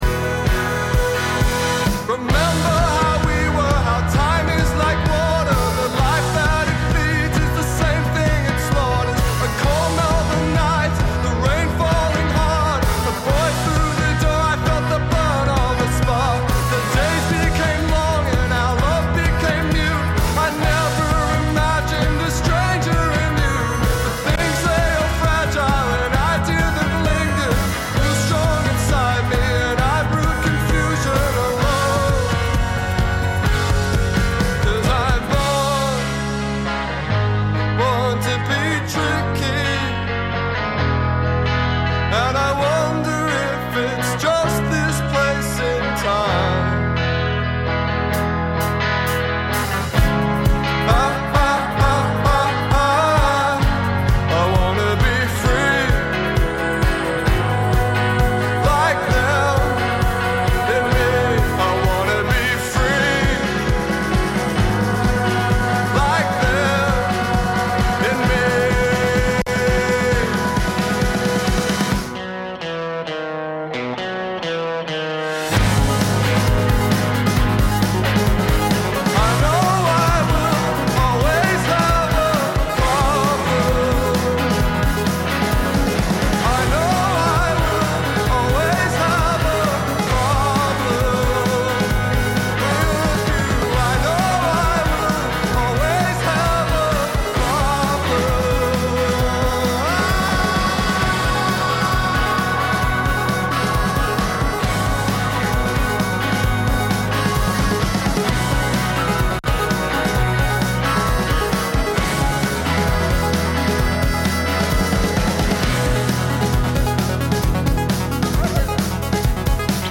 Werfen wir noch einmal einen "Blick" zur�ck in den vergangenen Monat, und genie�en Herausragendes, Banales und auch Peinliches (?) aus dem Programm von Radio F.R.E.I. Zusammengefasst und moderiert von wechselnden RedakteurInnen.